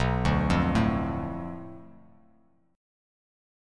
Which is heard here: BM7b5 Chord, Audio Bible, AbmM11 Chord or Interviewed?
BM7b5 Chord